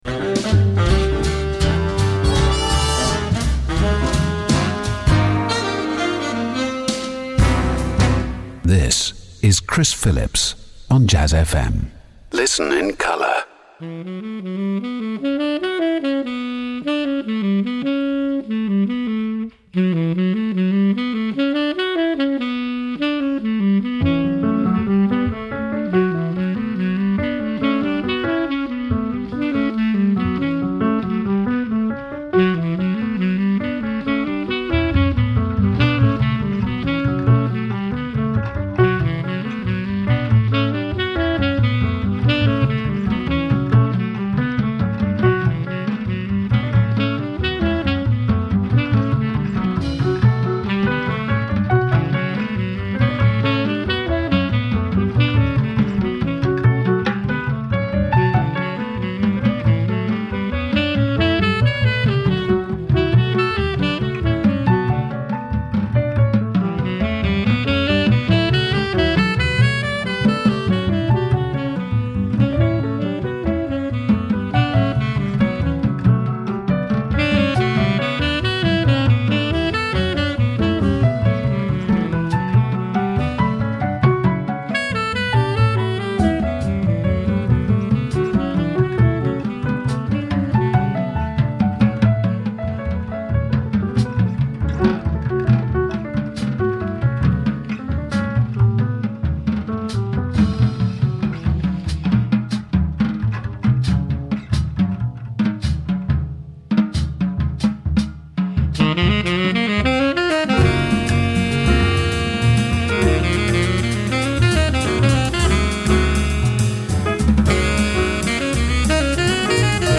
Interviews and Live Sessions